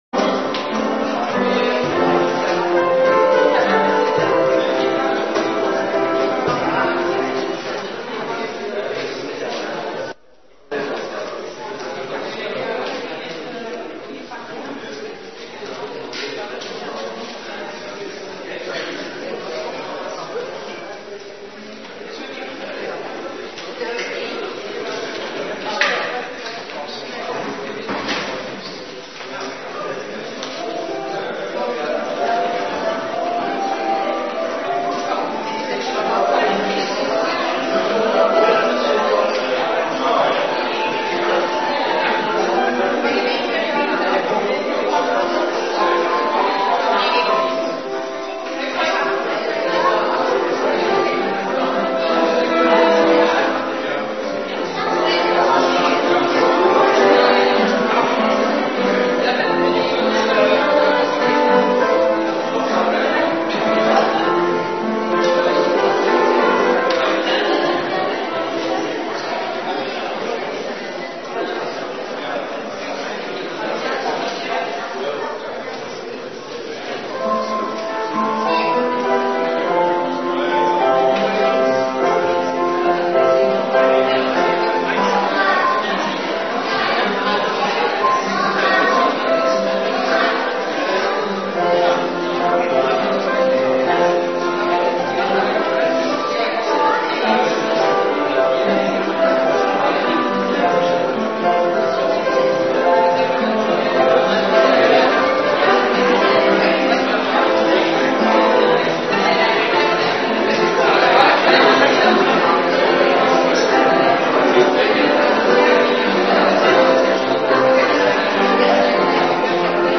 Orde van dienst voor jeugddienst zondag 17 februari 2019
Muziek en stilte Votum en groet (gezongen)